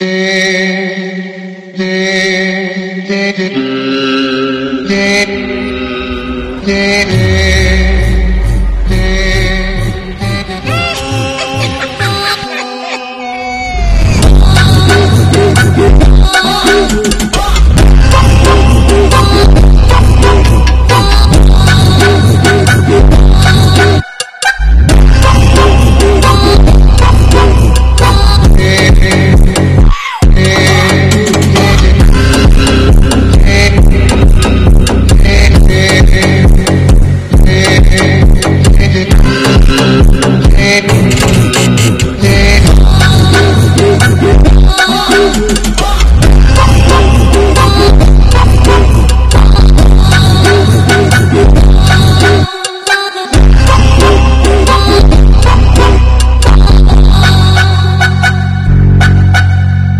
Fpv kamikaze drone freestyle ! sound effects free download